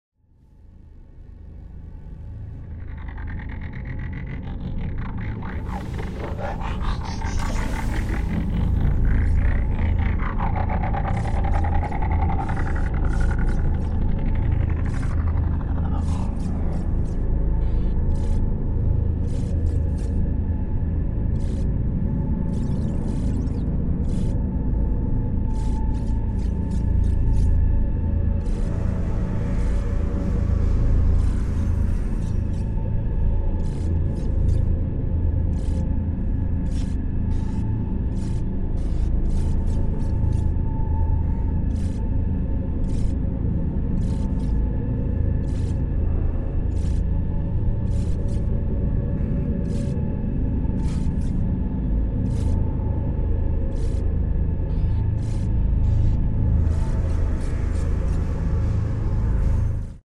Can you guess what movie this inspired ambience is from?! Check out more relaxing sounds like this and more!